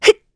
Isolet-Vox_Jump_kr.wav